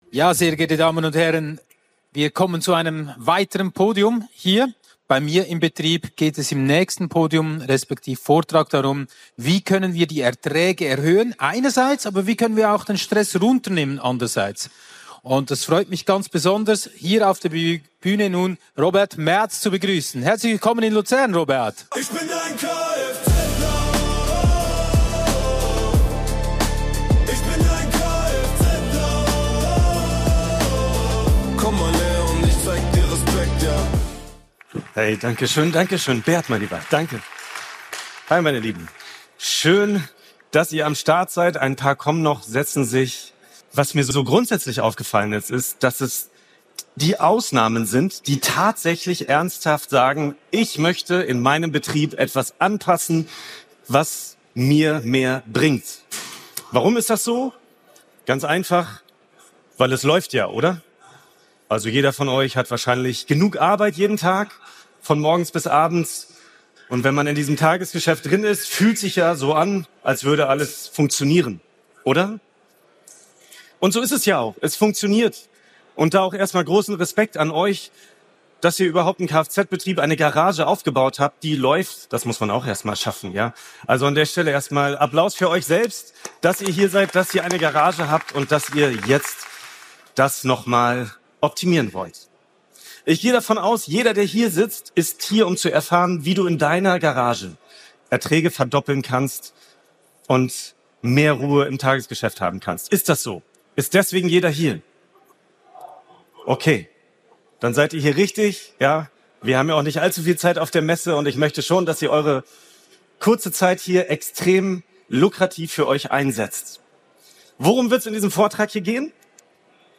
Autotechnikdays2026 Luzern, Schweiz ~ Aufstehen für das KFZ-HANDWERK Podcast